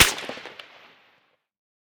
heav_crack_04.ogg